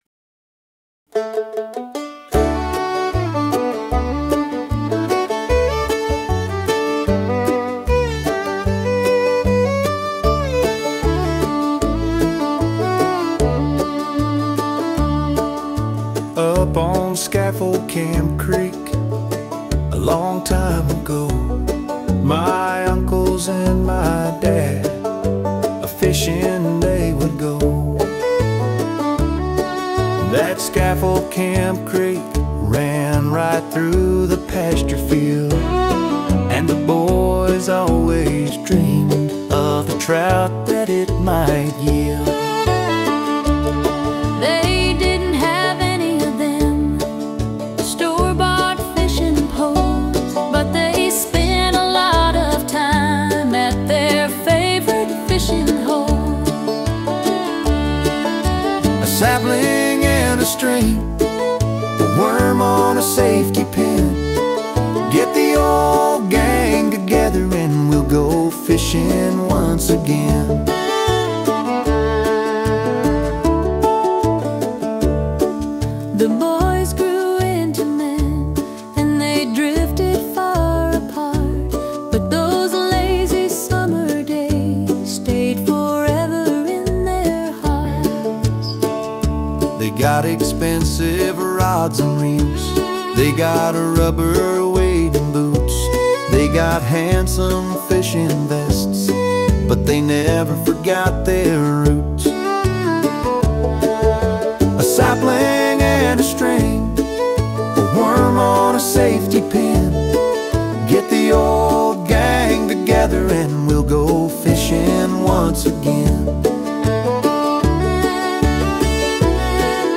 This evocative Bluegrass song was inspired by three things.